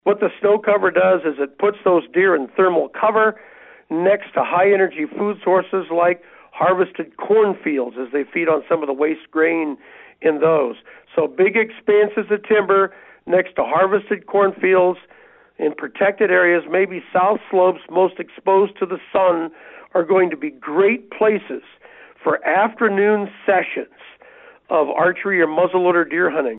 Weekly Conversation